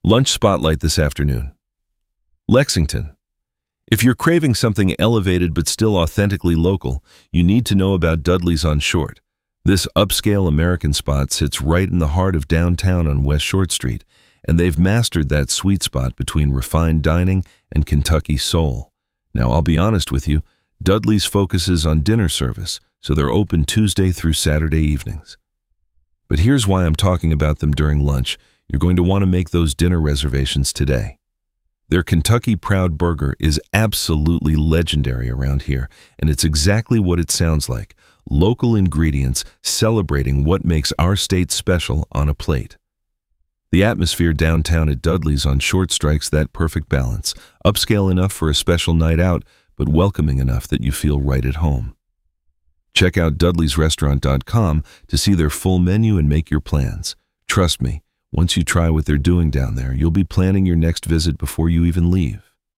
This transcript is from a recent on-air segment.
Voice synthesis via ElevenLabs; script via Claude.